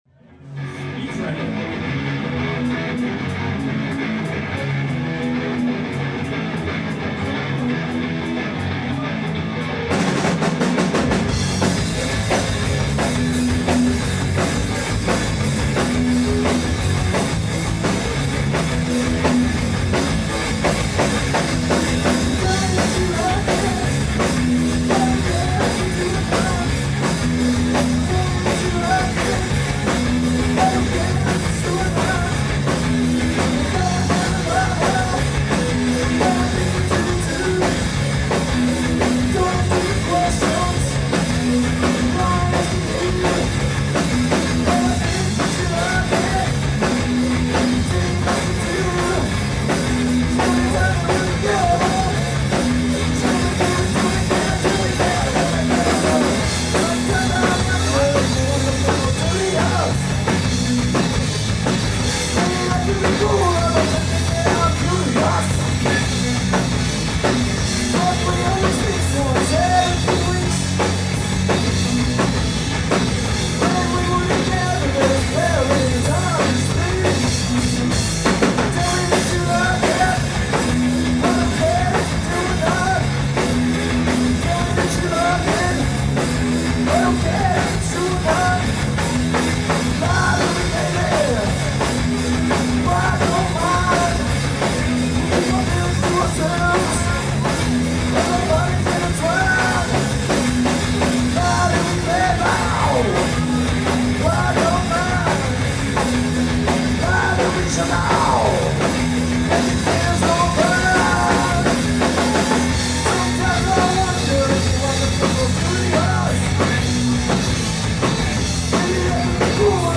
recorded this one live at Yuyintang earlier in the year
play blazing rock